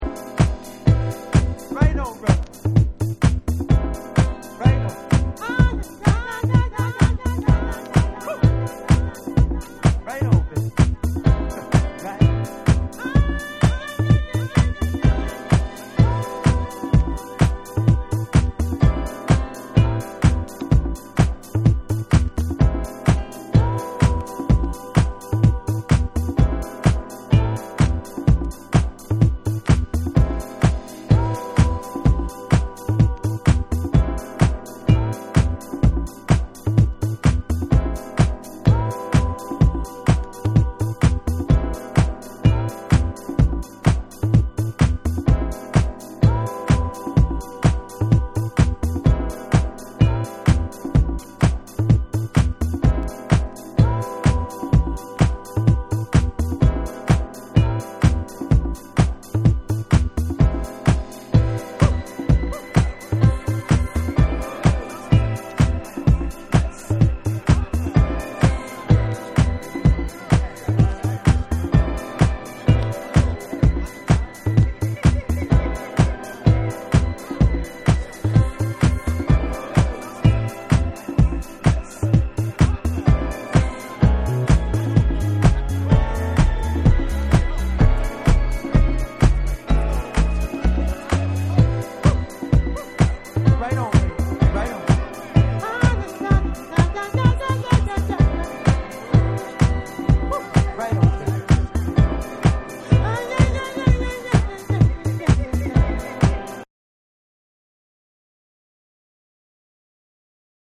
TECHNO & HOUSE / RE-EDIT / MASH UP